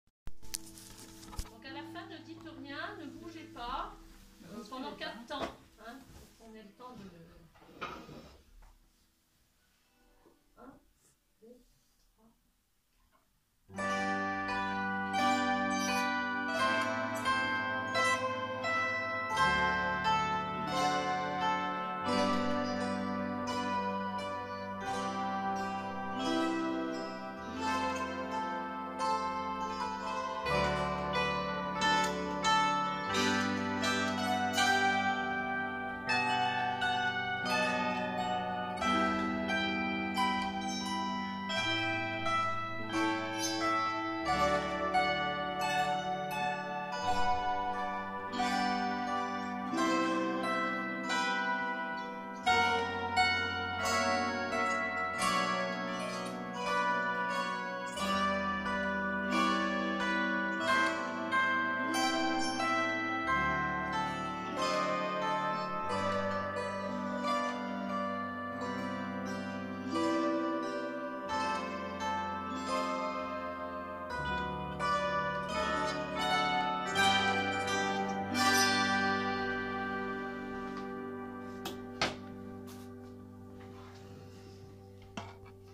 suite des souvenirs du concert anniversaire à l’abbaye d’En Calcat, donné par mes stagiaires le 28 octobre 2023
cantique
flûtiste